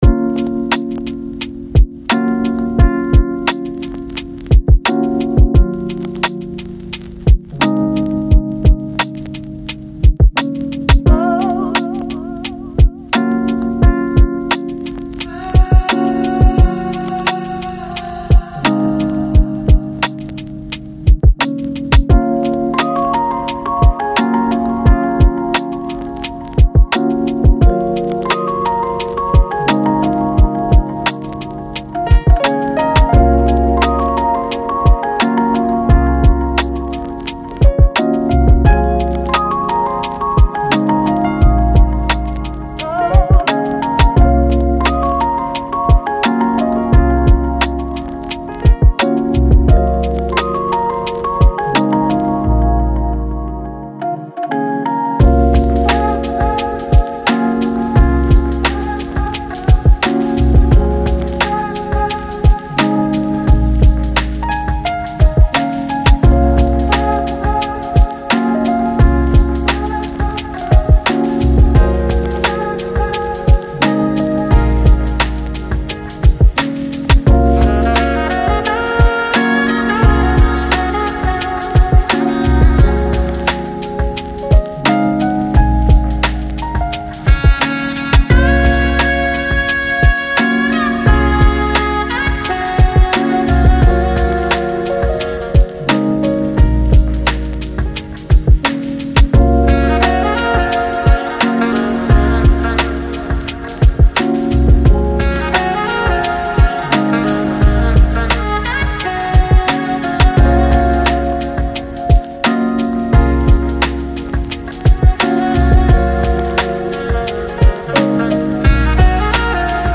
Lounge, Balearic, Downtempo, Smooth Jazz